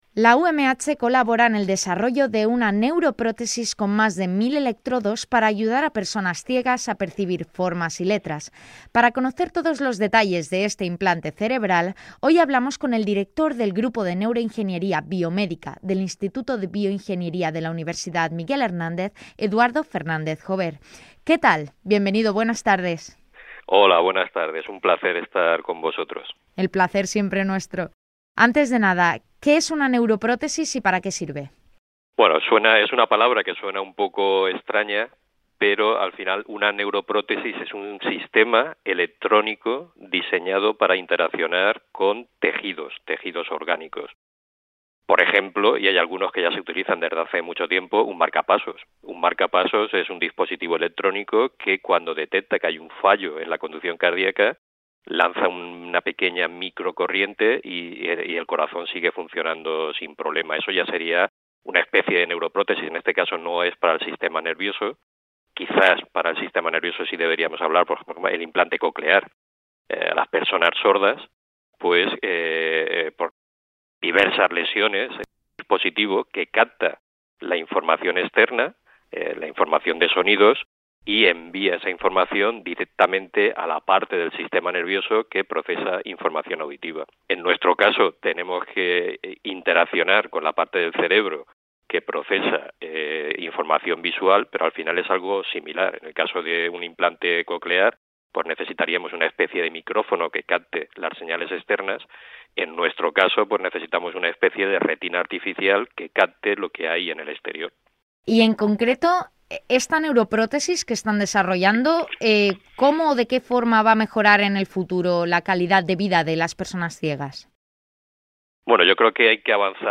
071220 Podcast INFORMATIVOS UMH